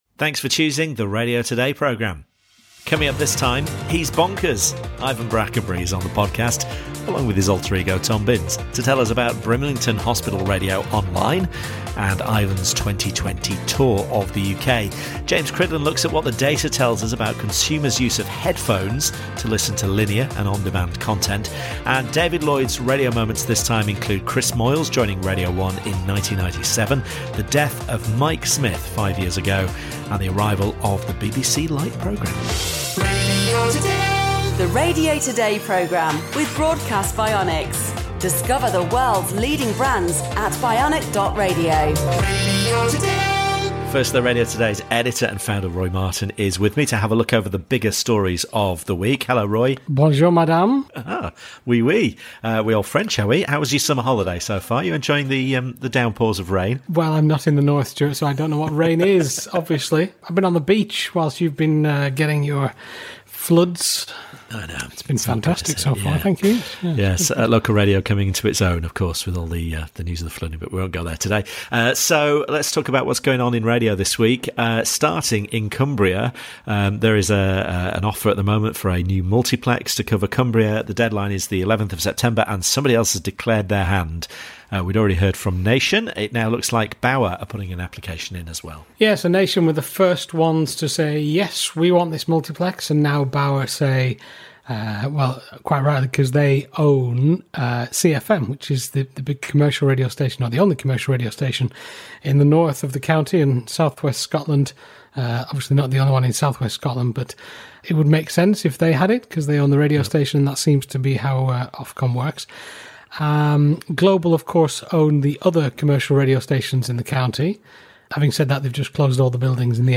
Ivan Brackenbury and his producer Tom Binns tell us about Brimlington Hospital Radio Online and Ivan’s 2020 UK tour - as well as about both of their radio careers.